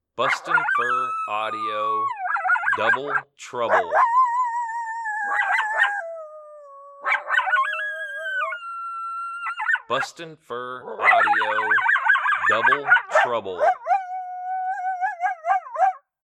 BFA's MotoMoto and Apollo pair howling together. As this howl progresses, excitement builds.